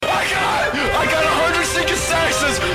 Scream